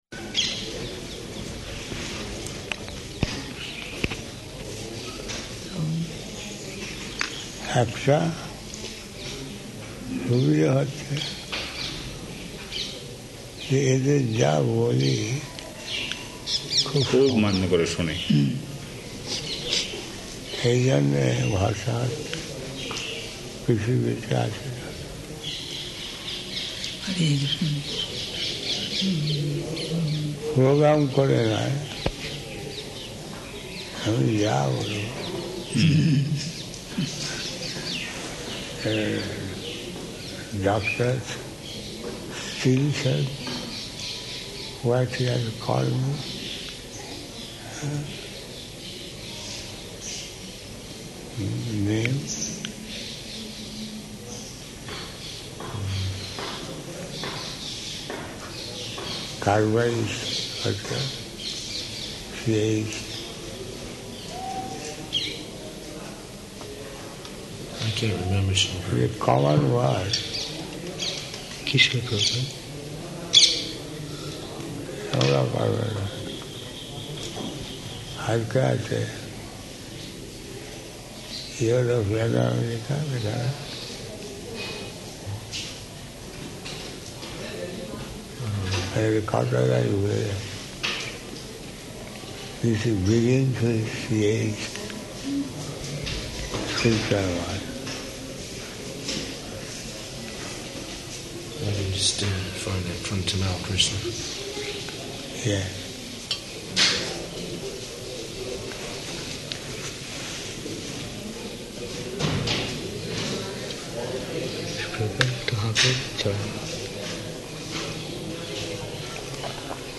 Room Conversation
Type: Conversation
Location: Vṛndāvana